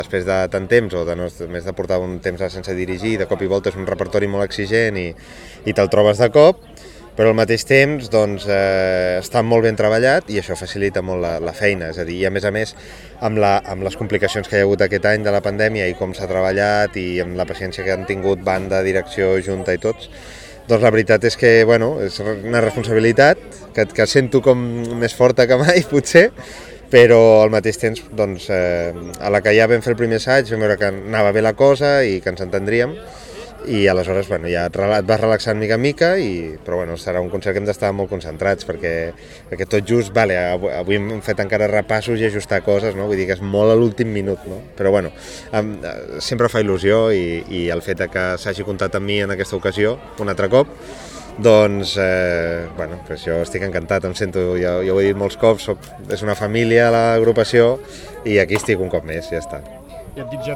Declaracions: